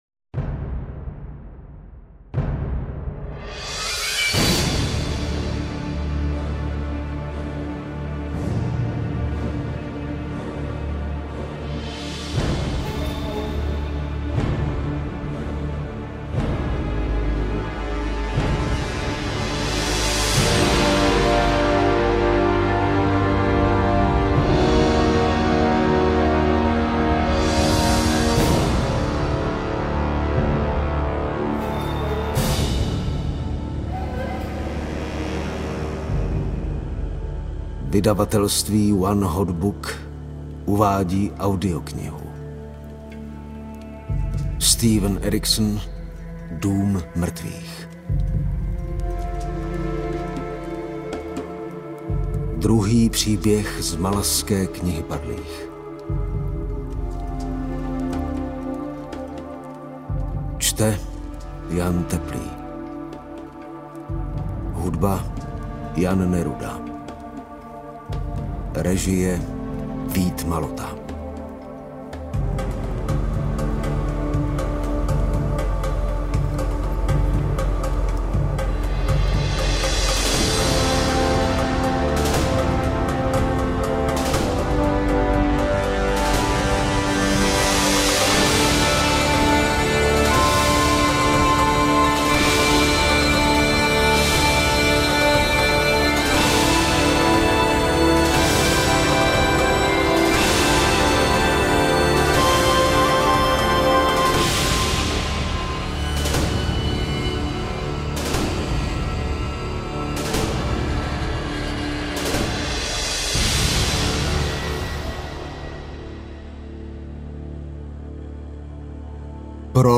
Interpret:  Jan Teplý
AudioKniha ke stažení, 110 x mp3, délka 40 hod. 49 min., velikost 2233,0 MB, česky